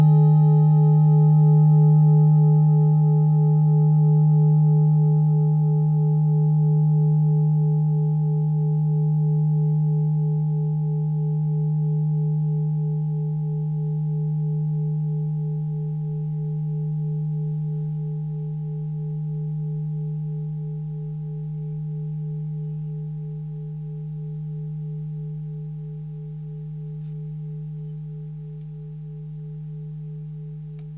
Klangschale Orissa Nr.2
(Ermittelt mit dem Filzklöppel)
klangschale-orissa-2.wav